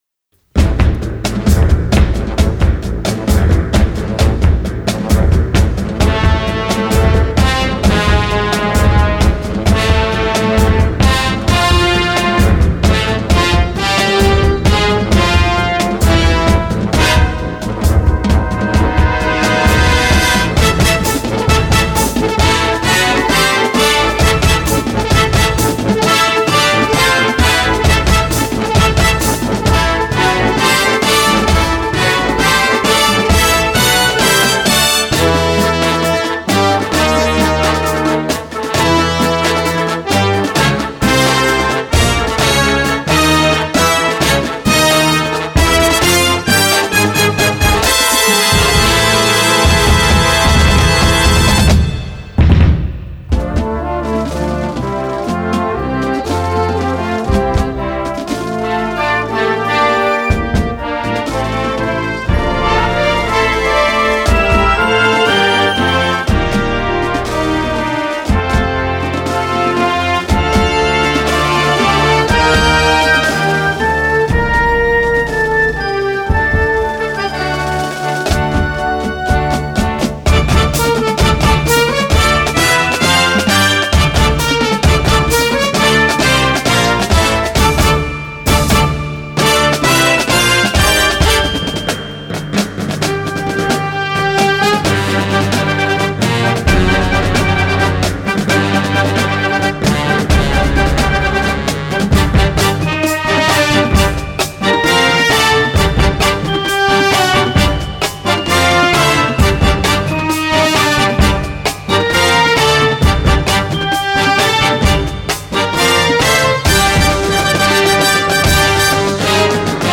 Gattung: Filmmusik
Marching-Band
Besetzung: Blasorchester